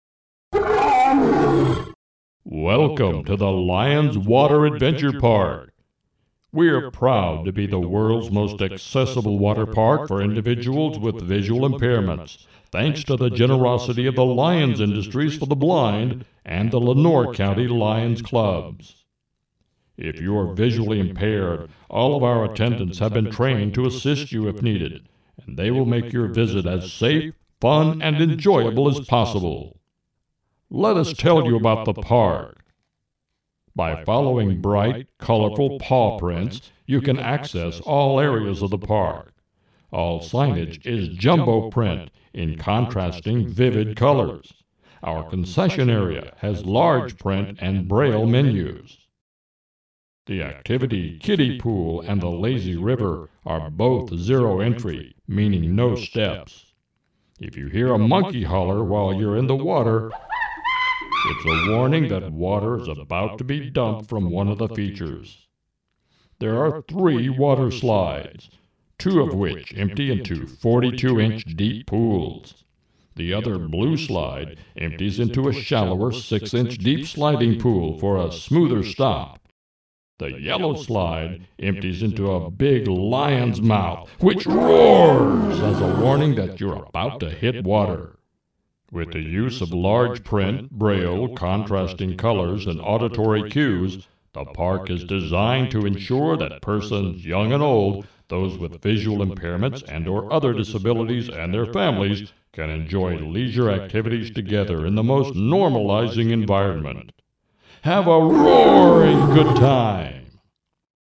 Listen to Swimba describe our park's features designed for visually impaired guests.